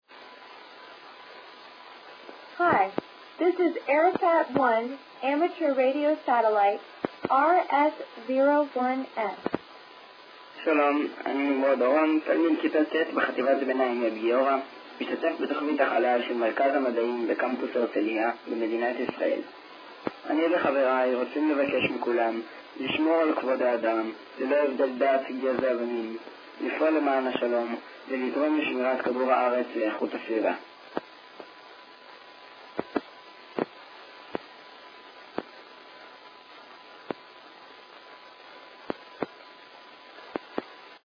Звуковые сообщения с Кедр 08.08.2011
Приветствие 4 (Израиль):